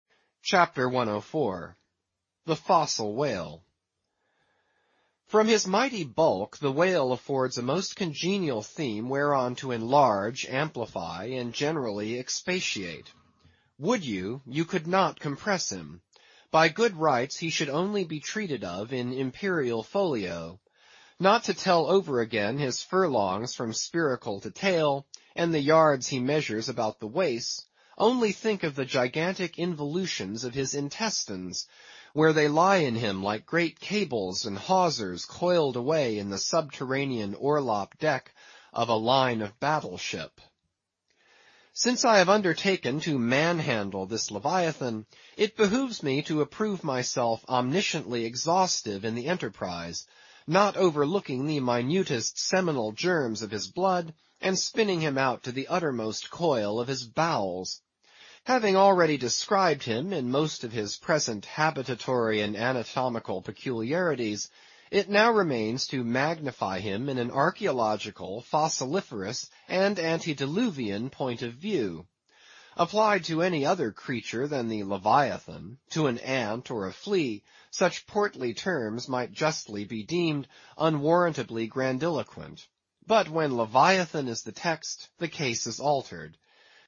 英语听书《白鲸记》第867期 听力文件下载—在线英语听力室